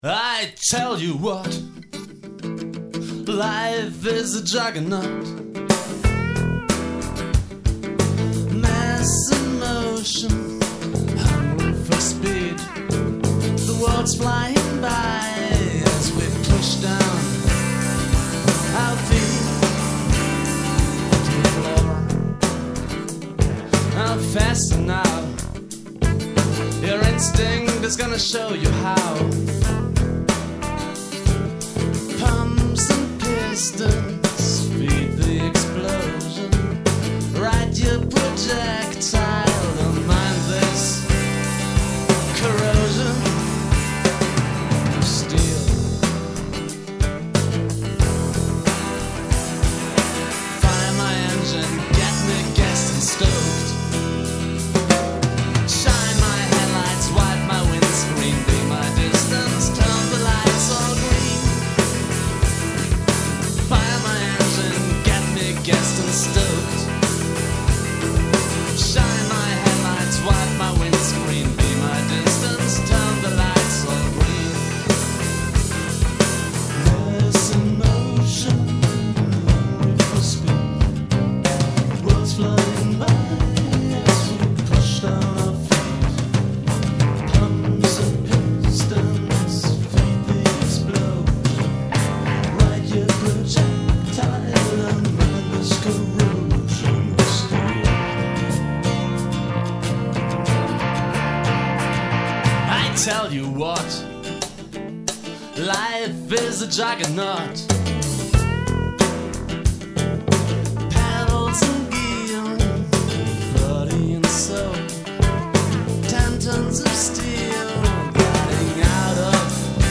Echte Musik, echte Instrumente, echte Männer.
MoodyMelodicRockPop vom Feinsten.